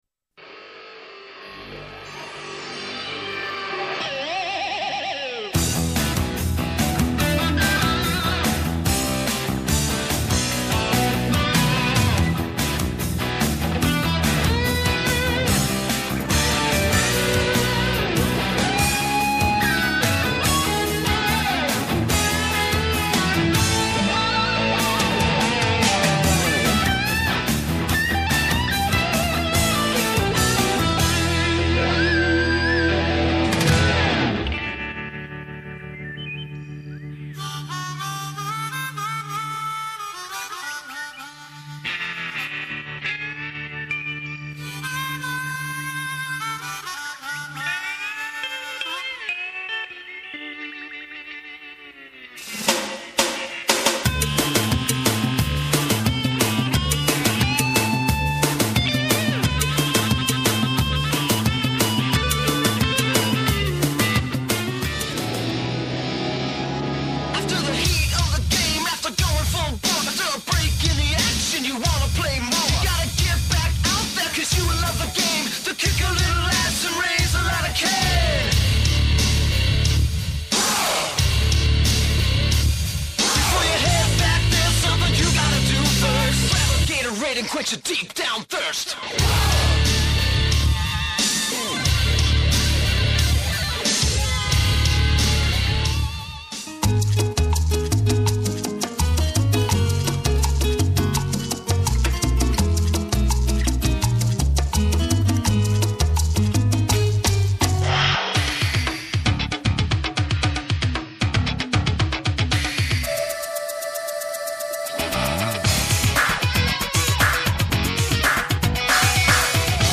Studio Demo #2 (mp3 - 3.05 MB)